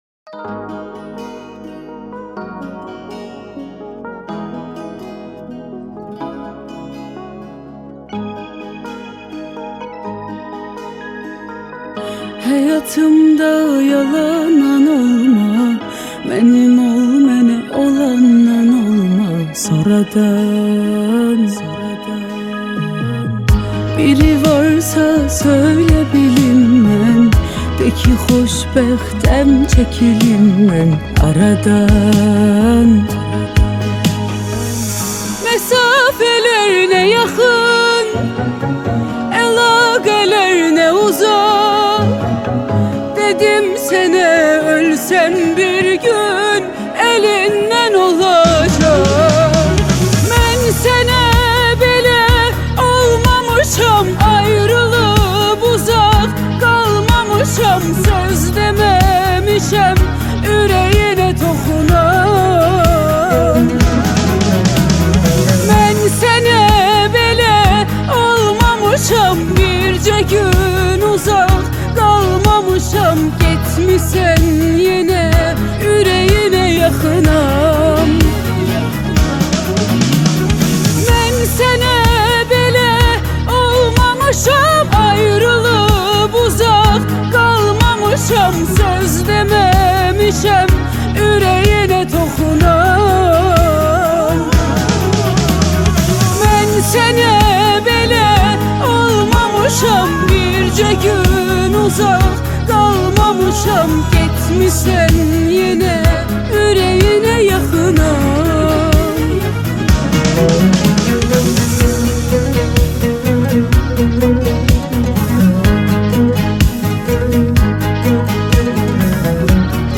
Azeri Muzik